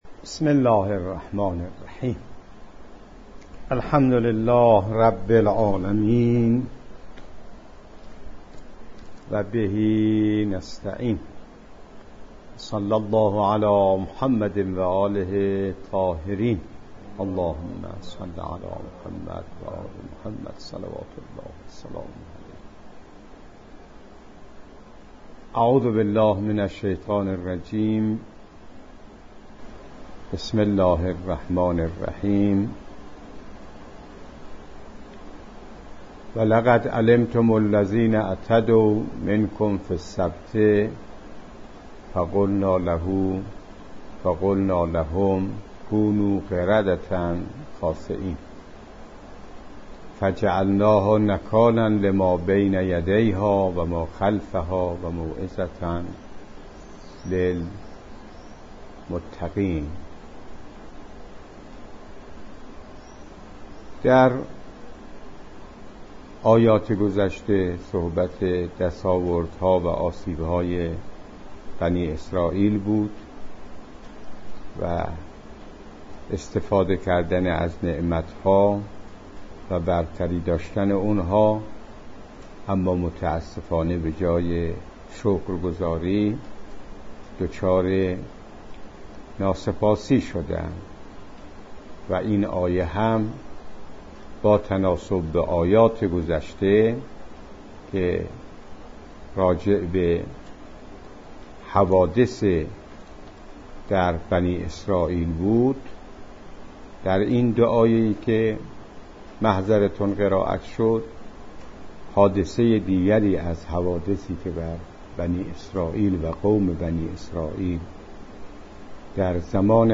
جلسه درس تفسیر قرآن کریم